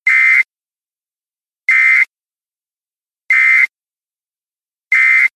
Radio Interruption
radio_interruption.mp3